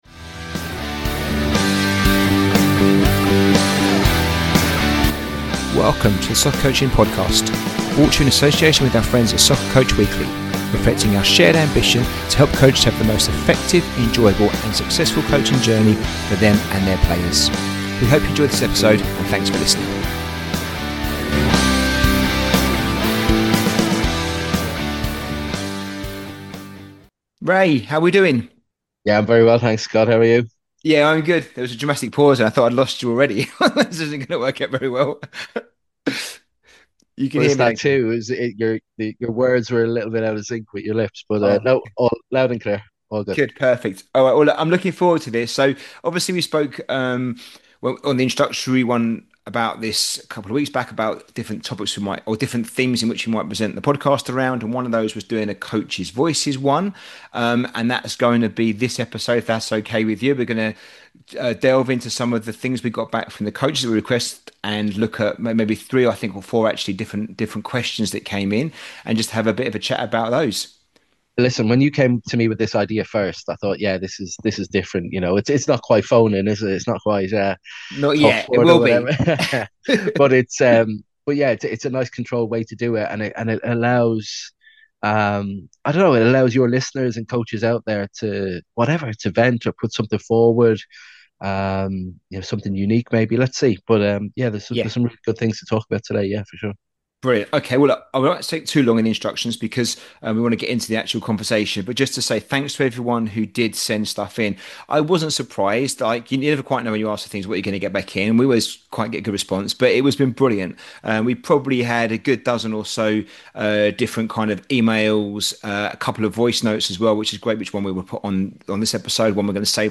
These are real challenges from real environments, and the discussion is honest, practical, and grounded in lived coaching experience. This episode is less about theory — and more about the realities of coaching in today’s game.